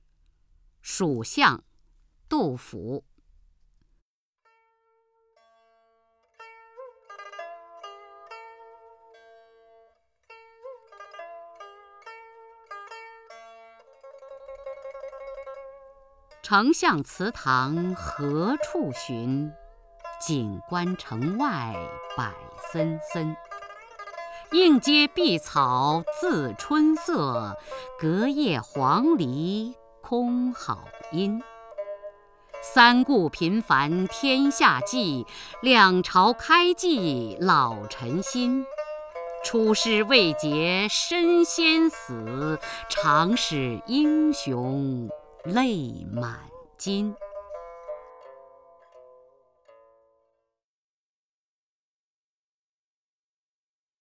雅坤朗诵：《蜀相》(（唐）杜甫) （唐）杜甫 名家朗诵欣赏雅坤 语文PLUS